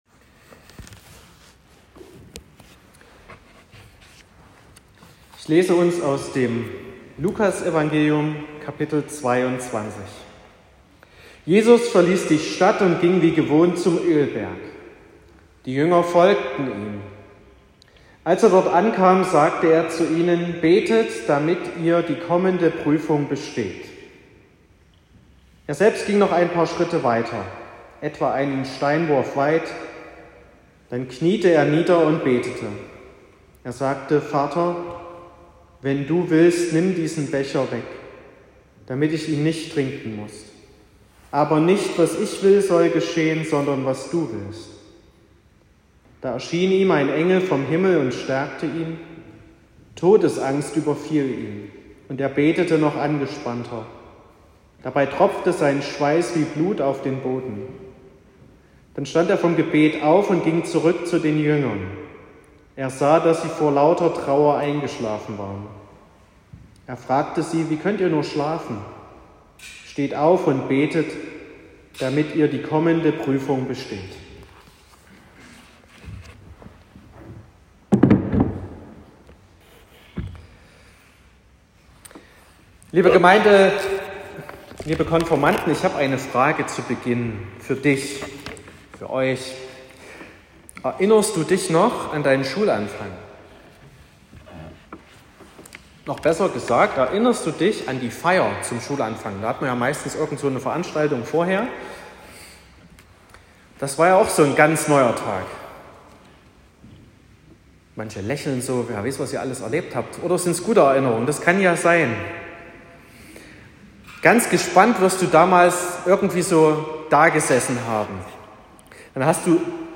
06.04.2023 – Gottesdienst mit Erstabendmahl der Konfirmanden Klasse 7
Predigt und Aufzeichnungen